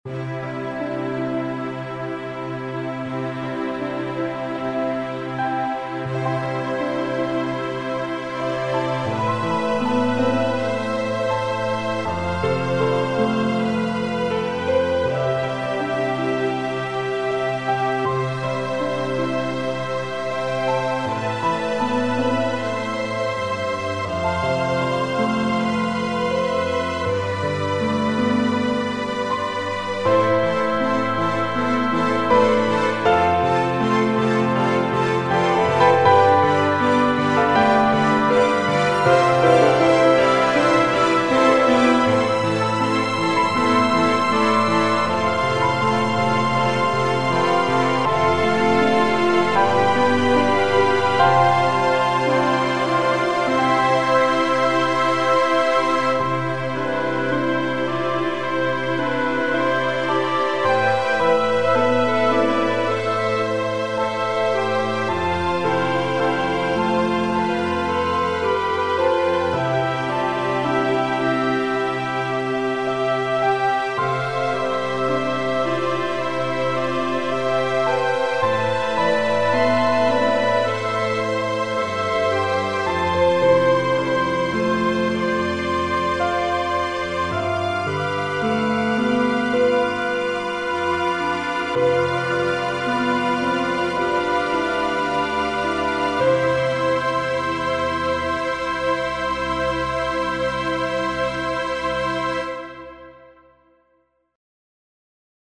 TiMidity++にて録音(1.61 MB)   クリスマスだね。
比較用に、TiMidity++による録音版も入れてみます。